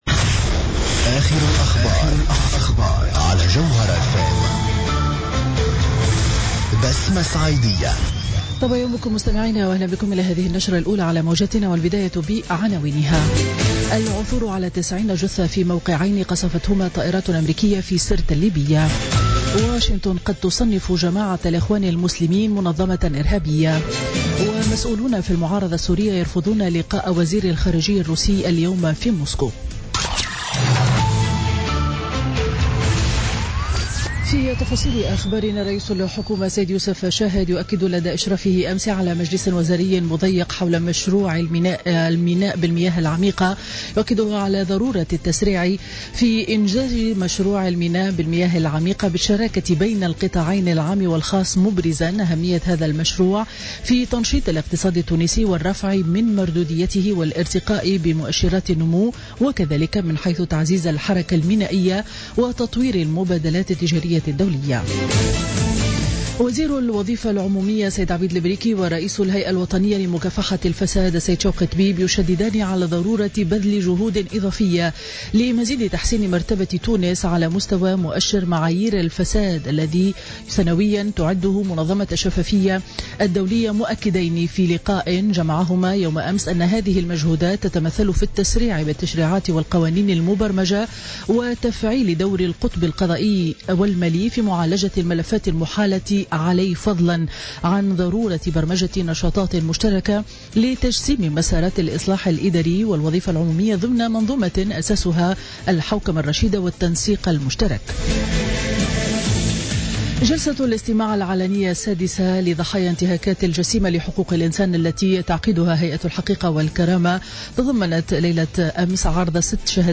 نشرة أخبار السابعة صباحا ليوم الجمعة 27 جانفي 2017